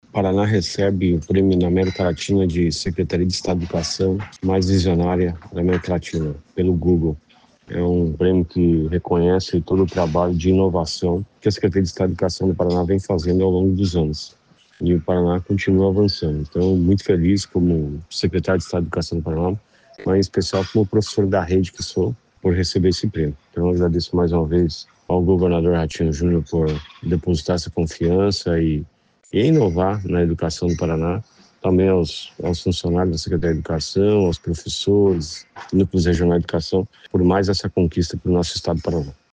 Sonora do secretário da Educação, Roni Miranda, sobre o prêmio 'Secretaria Visionária da América Latina’